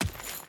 Footsteps
Dirt Chain Run 1.wav